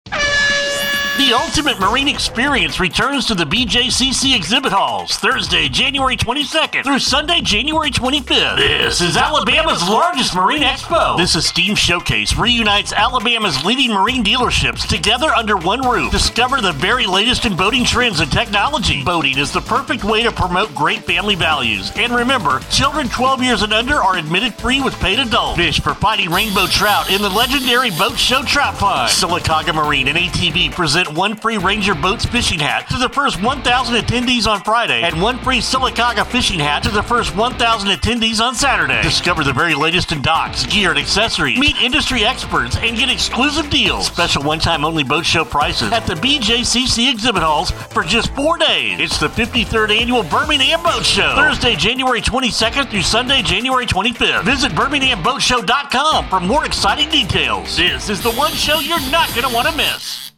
2026radio-ad.mp3